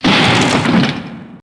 1 channel
Doorbrk1.mp3